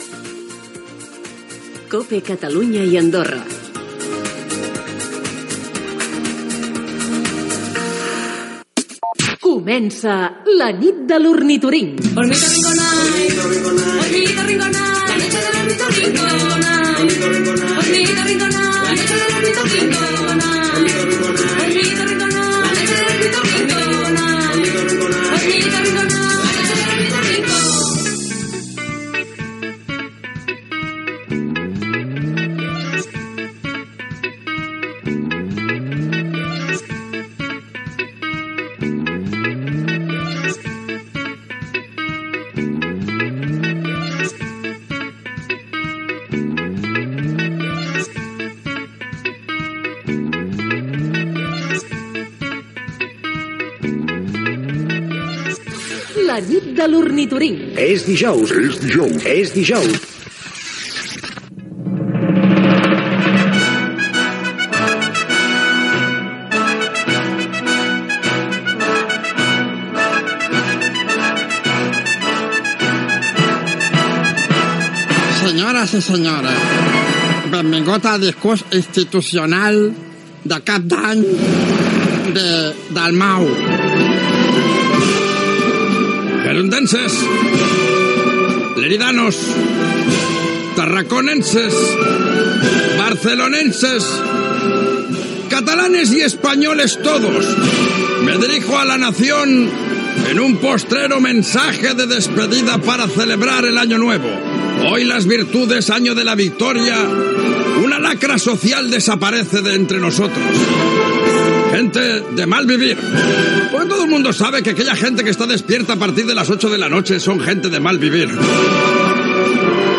Indicatiu de la cadena, sintonia cantada del programa